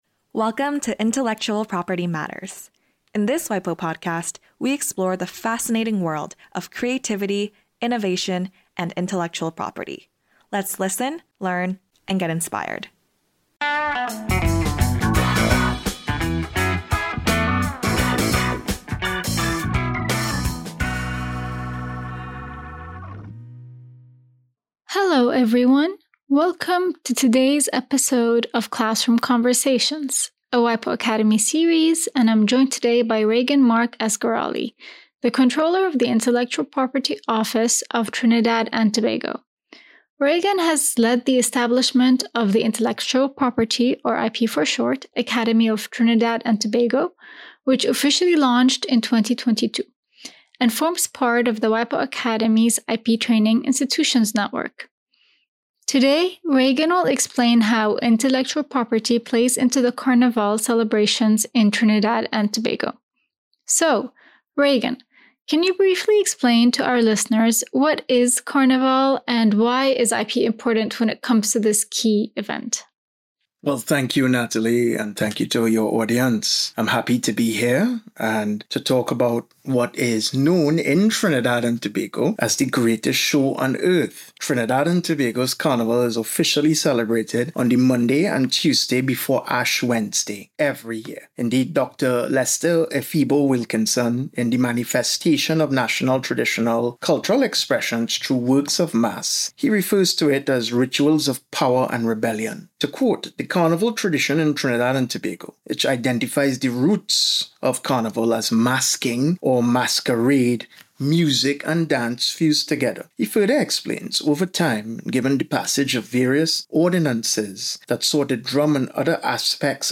Regan Mark Asgarali, the Controller of the Intellectual Property Office of Trinidad and Tobago, explains how IP is a core element of the Carnival, enabling people tocelebrate their culture and contribute to the social economy of the country. Classroom Conversations – bite-sized crash courses and conversations on IP topics featuring real life examples from around the world – are brought to you by the WIPO Academy.